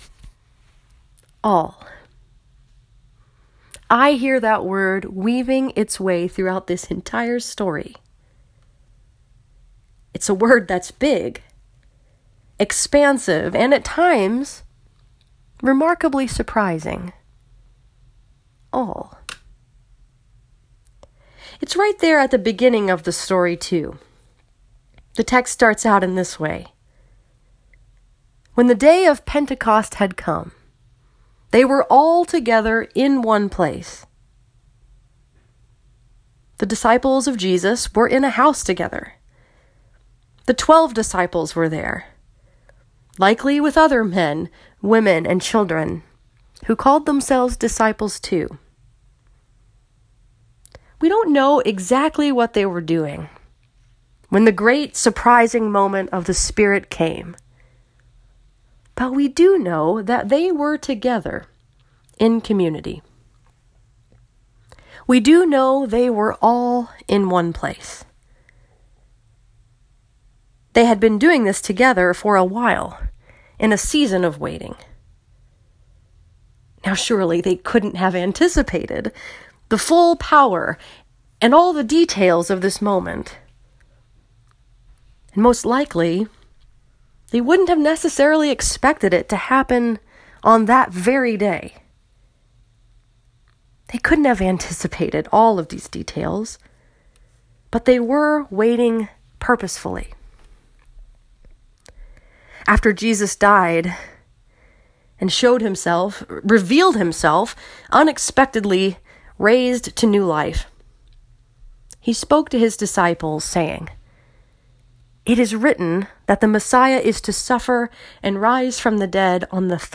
This sermon was preached at First Presbyterian Church in Saline, Michigan and was focused upon the story that is told in Acts 2:1-21.